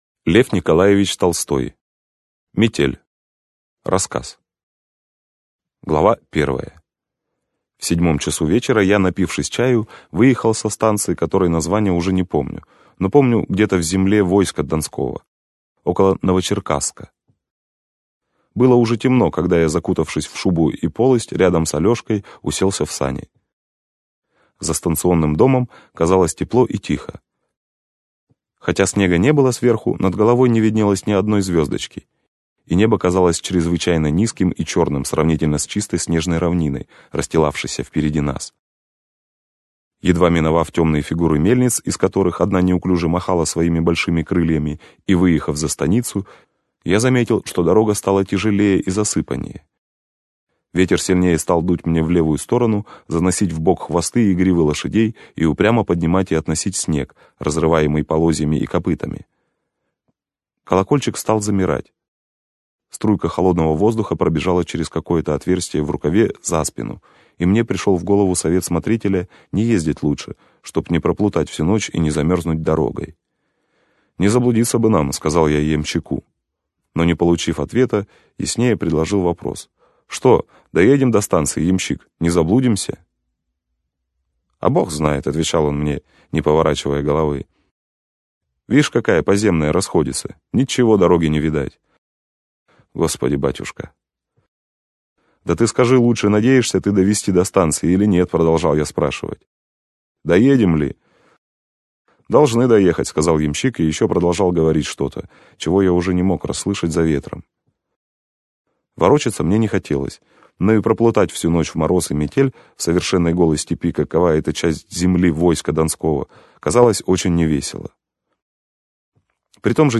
Аудиокнига Метель | Библиотека аудиокниг